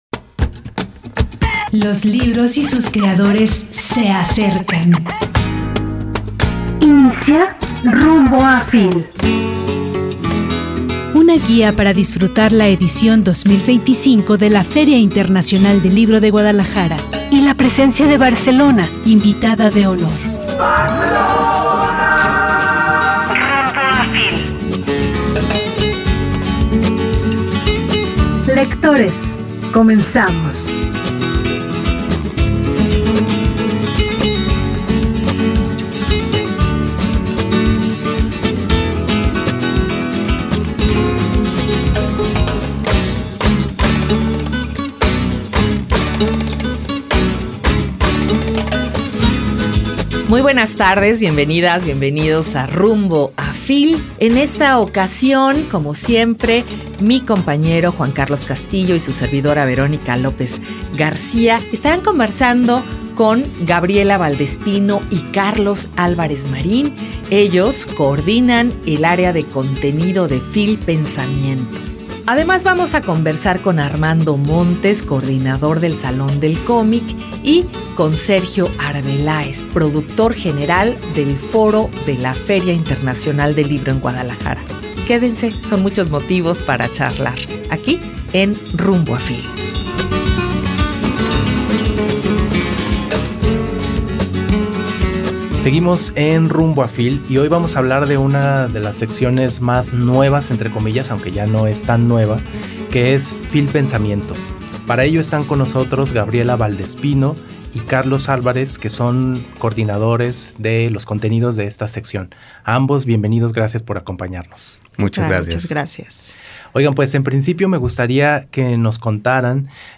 Nuestra última emisión de Rumbo a FIL 2025 estuvo nutrida.